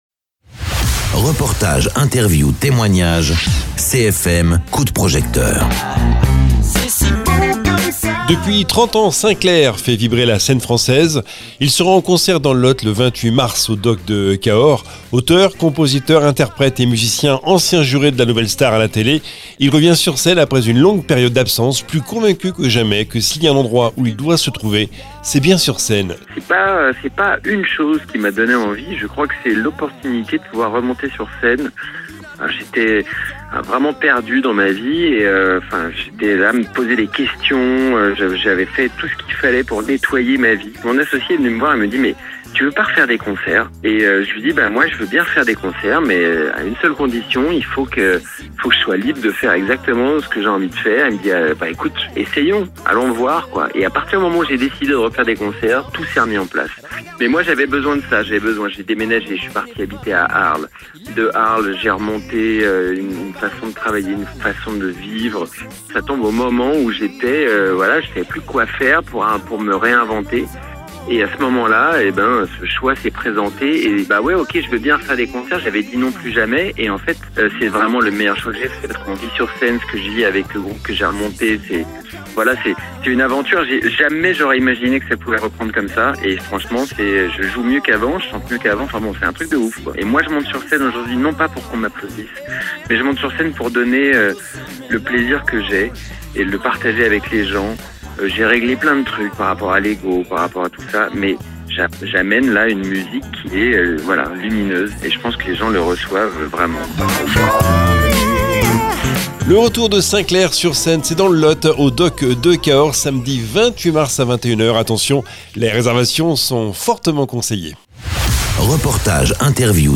Interviews
Invité(s) : Sinclair, auteur, compositeur, interprète, musicien, ex-juré "Nouvelle star"